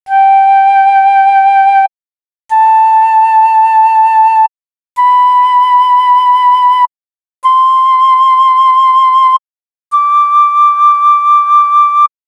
flute.wav